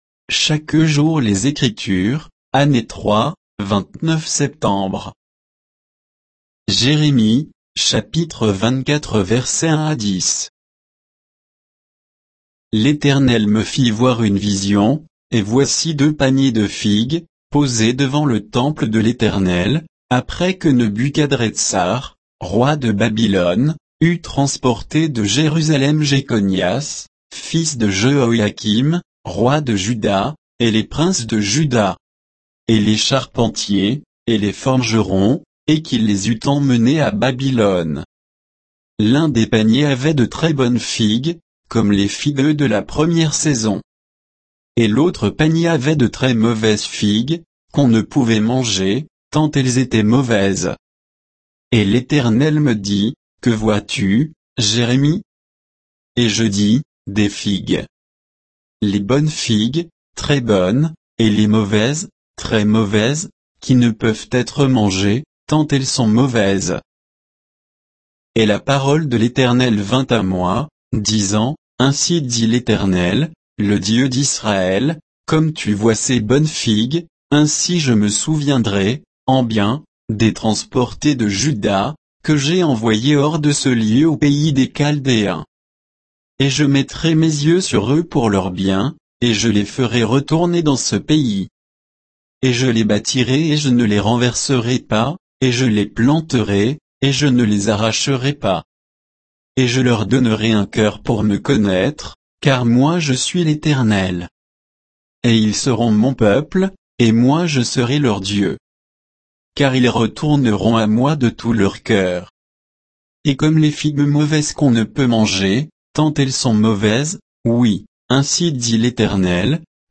Méditation quoditienne de Chaque jour les Écritures sur Jérémie 24